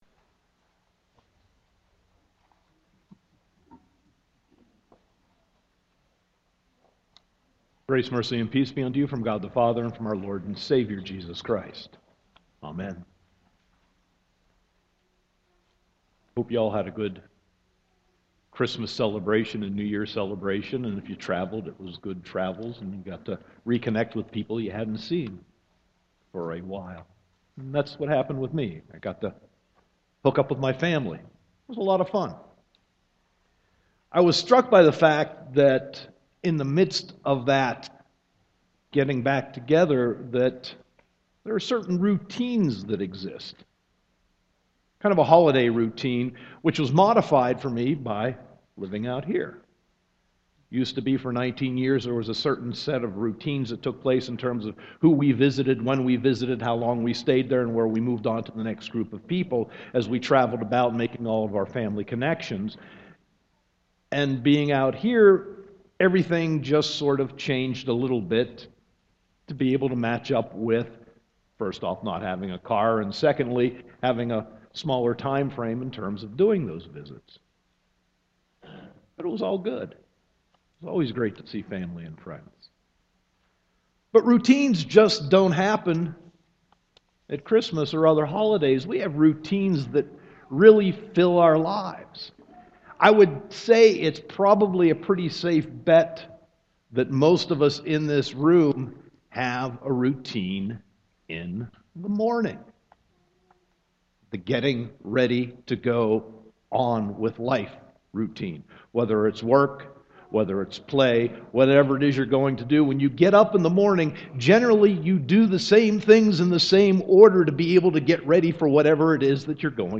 Sermon 1.4.2015